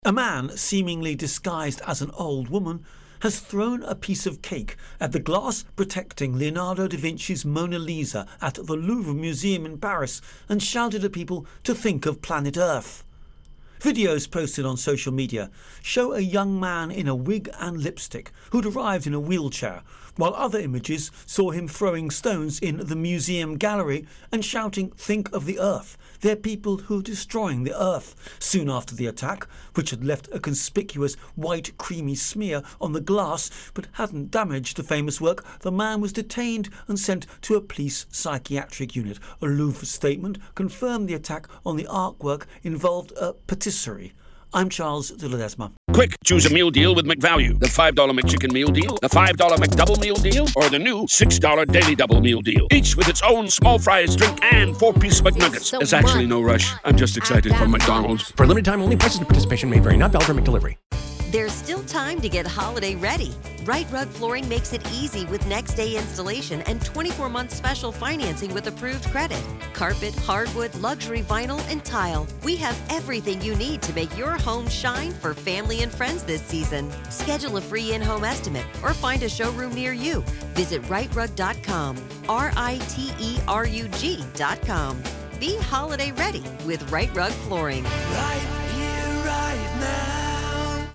France-Mona Lisa Intro and Voicer